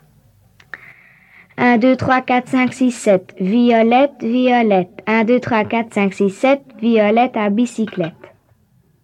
Genre : chant
Type : comptine, formulette
Interprète(s) : Anonyme (enfant)
Support : bande magnétique
"Comptine." (Note du collecteur)